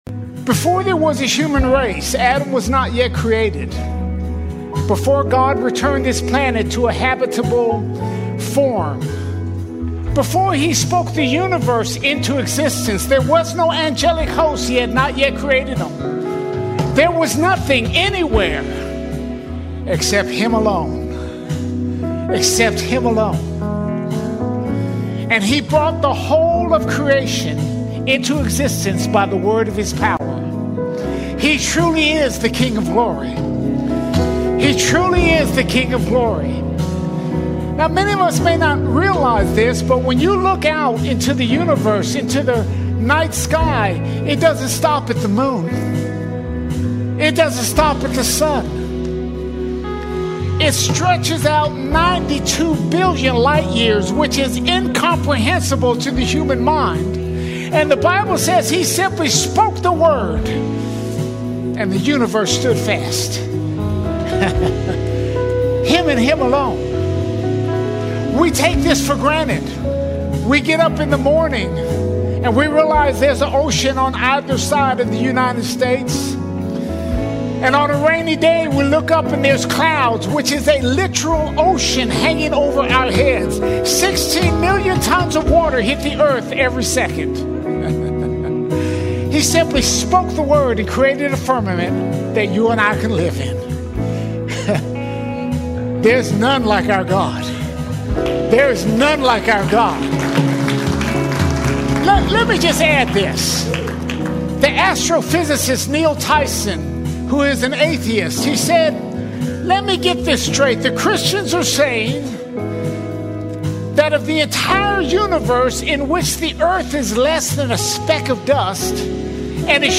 15 March 2026 Series: Sunday Sermons All Sermons The War Against God The War Against God We live in a world at war with God.